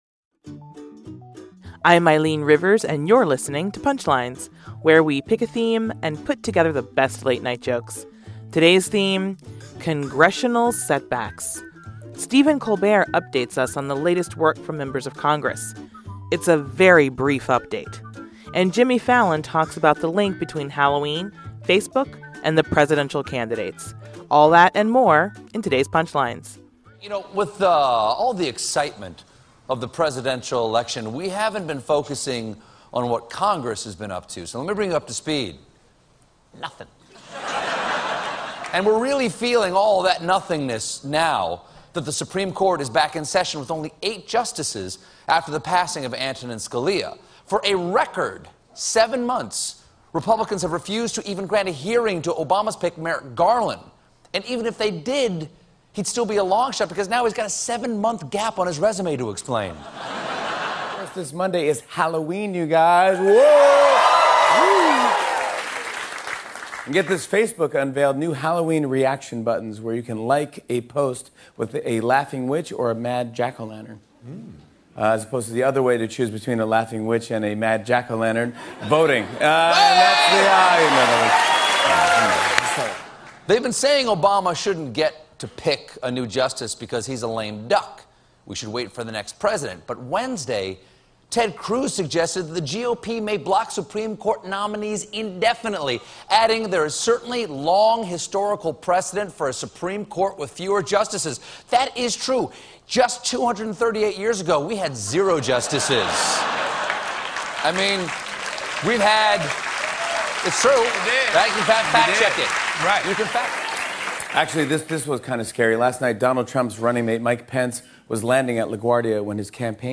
The late-night comics on the latest political news, including the do-nothing Congress and SCOTUS slowdown.